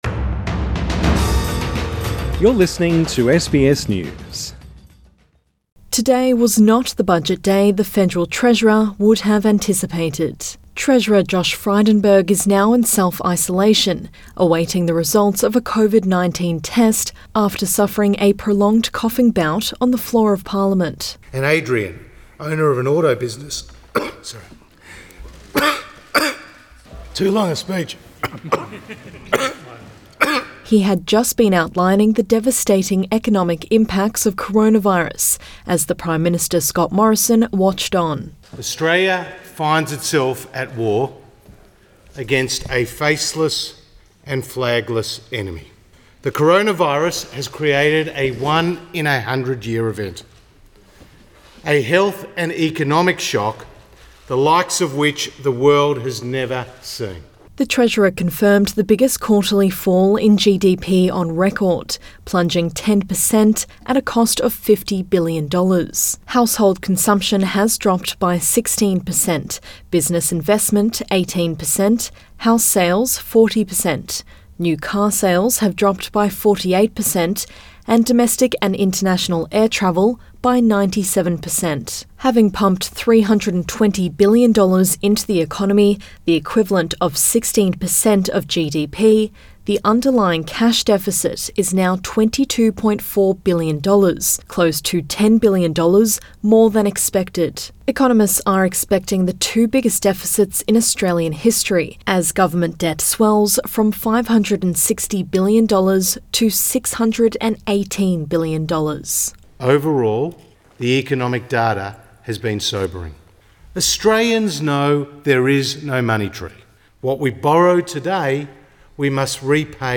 Treasurer chokes delivering 'sobering' economic outlook
Treasurer Josh Frydenberg makes a ministerial statement to the House of Representatives Source: AAP